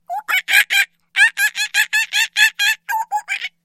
На этой странице собраны разнообразные звуки шимпанзе — от радостных возгласов до предупреждающих криков.
Голос шимпанзе в мультфильме Планета обезьян